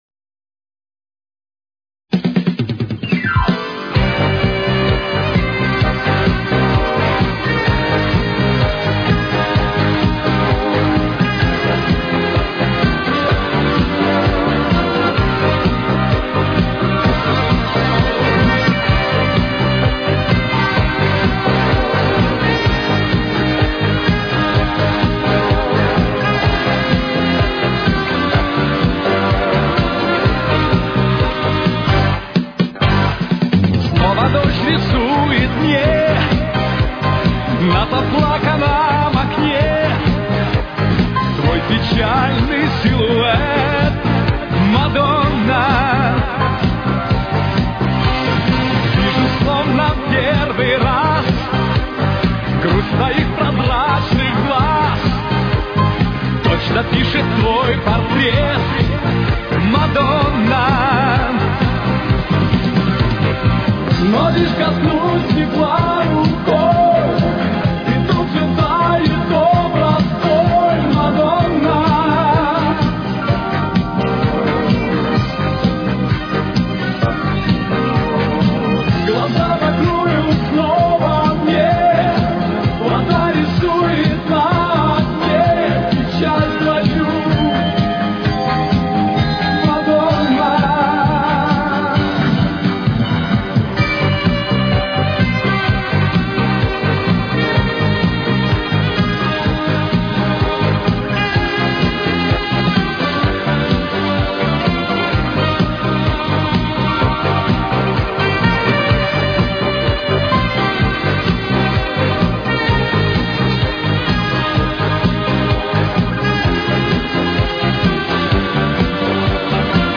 Темп: 131.